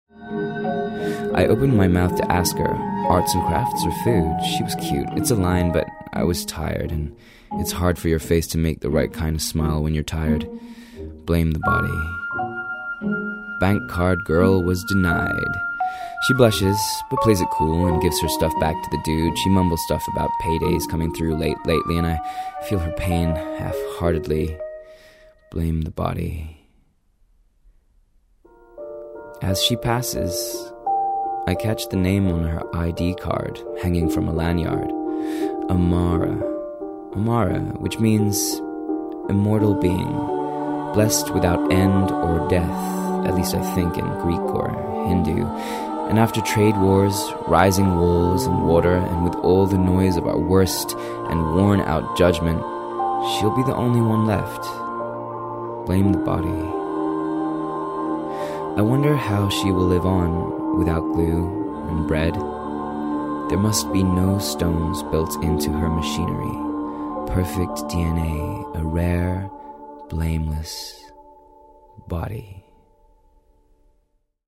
Podcast Showreel
Male
American Standard
British RP
Confident
Friendly
Youthful
Warm
Upbeat